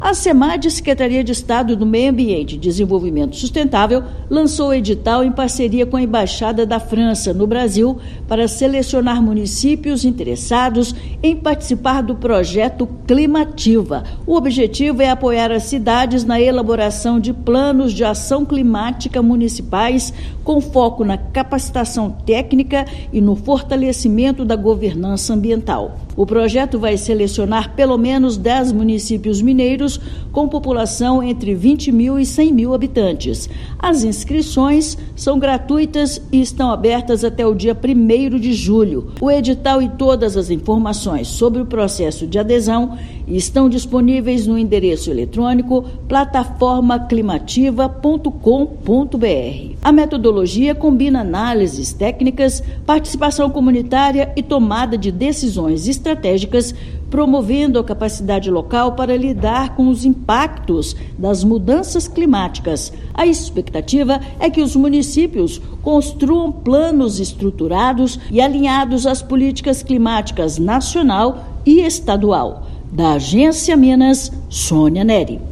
A iniciativa pretende capacitar ao menos dez municípios mineiros para enfrentar os desafios das mudanças climáticas. Ouça matéria de rádio.